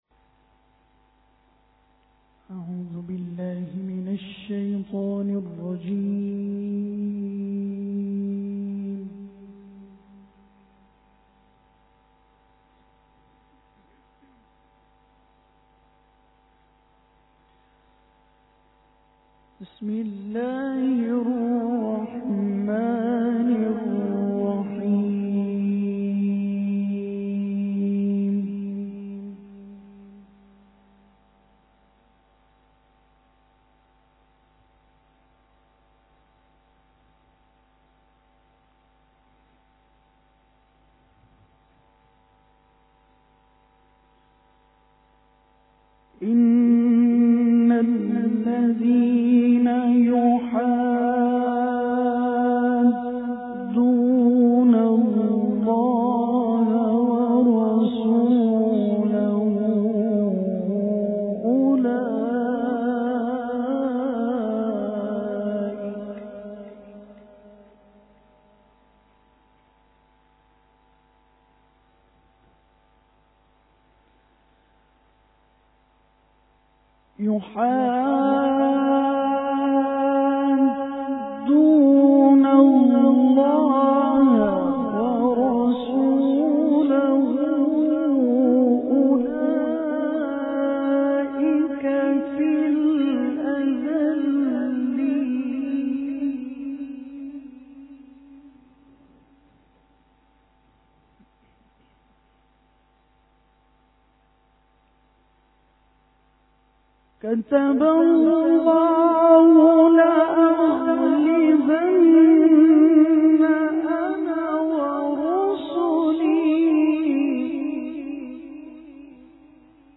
Tilawat e Quran (Surah Mujadila)
تلاوت قرآن
tilawat-e-quran-surah-mujadila.mp3